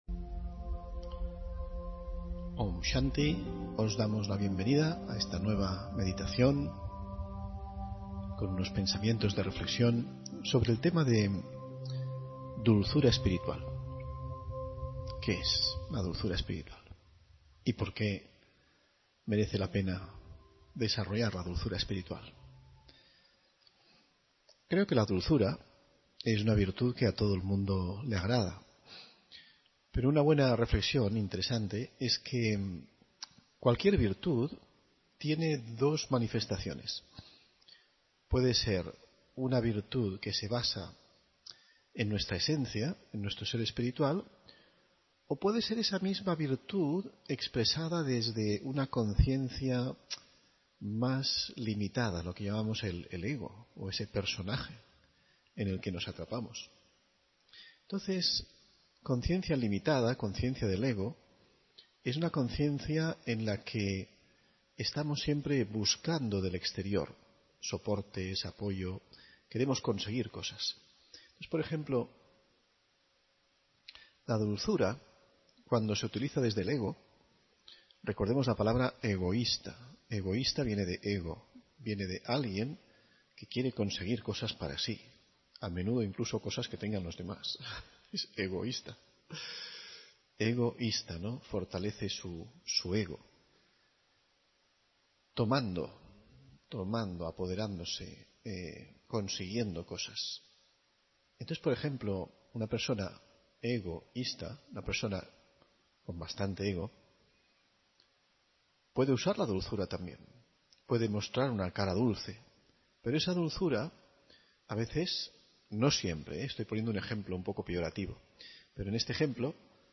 Meditación y conferencia: Dulzura espiritual (24 Enero 2024)